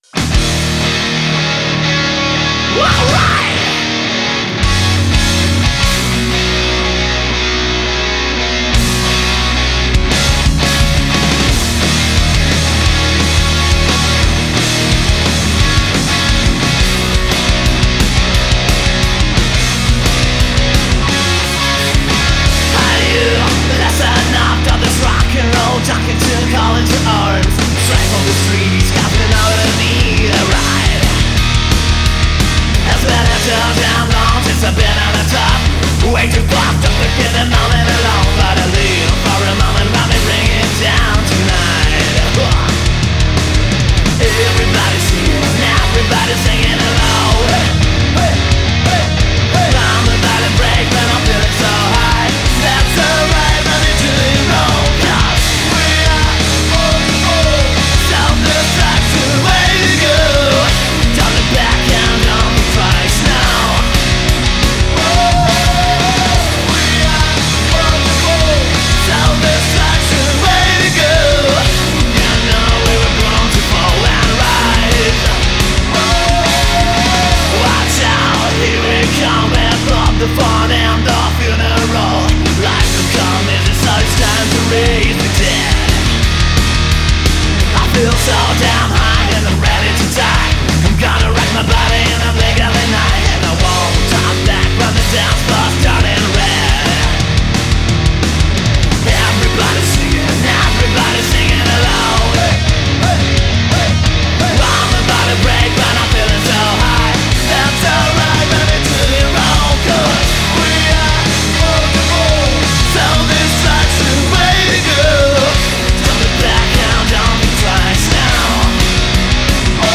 Genre: hardrock.